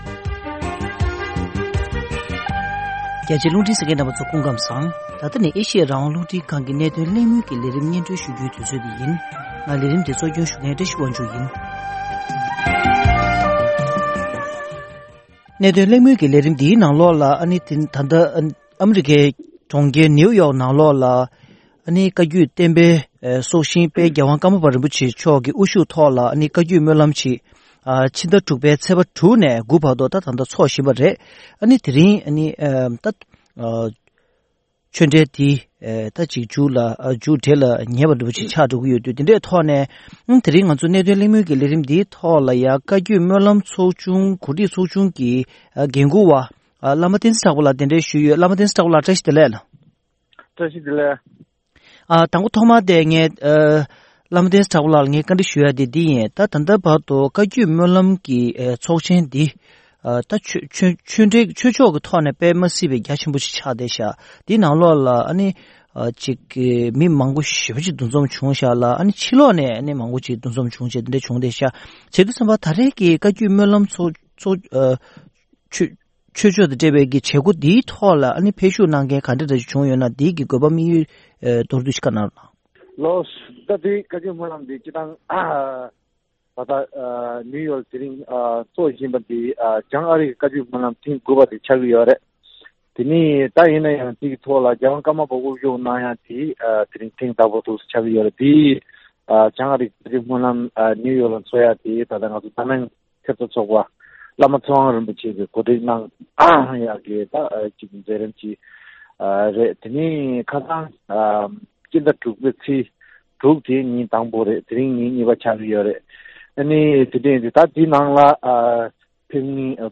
༄༅། །ཐེངས་འདིའི་གནད་དོན་གླེང་མོལ་གྱི་ལས་རིམ་འདིའི་ནང་།